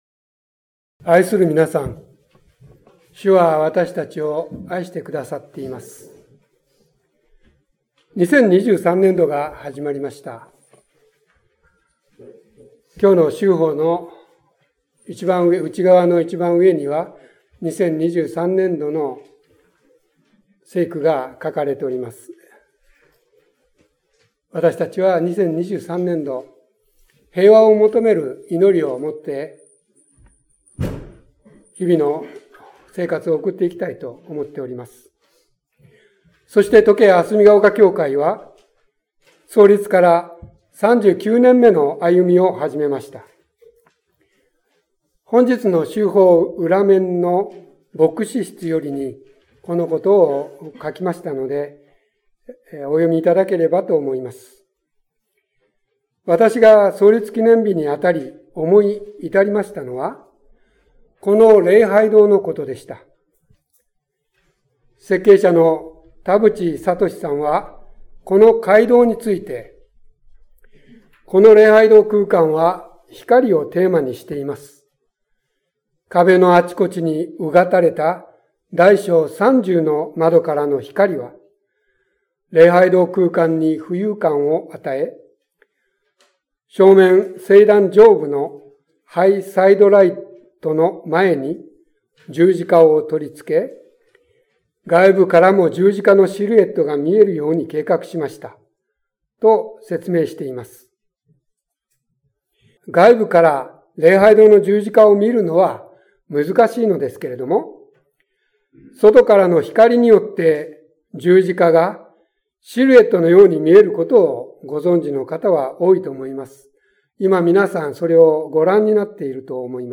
4月2日礼拝説教「イエスは子ろばに」